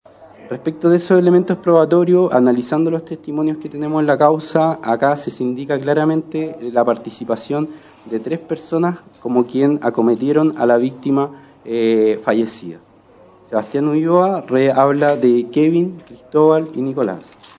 El juez Pablo Billar, del Juzgado de Garantía de San Bernardo, entregó mayores detalles en la audiencia.
med-cuna-magistrado.mp3